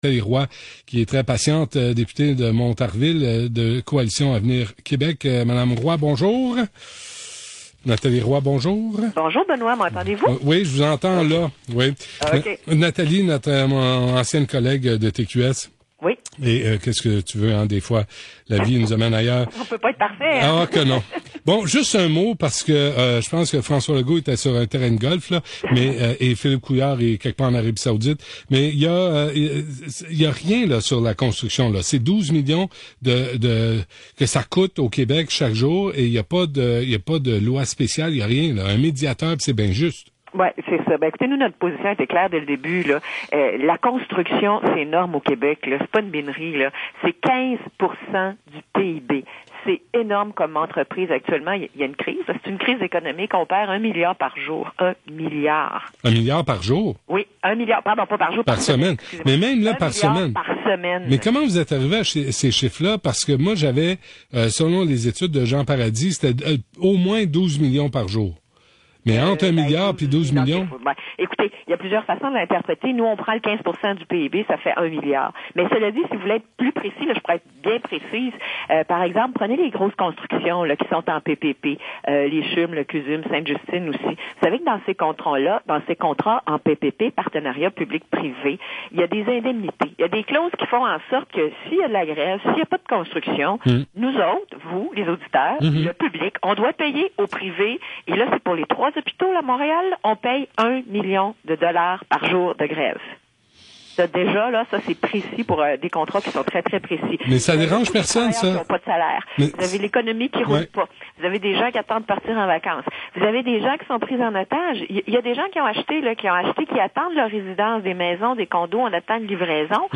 AUDIO: Nathalie Roy discute avec Benoit Dutrizac du 98.5FM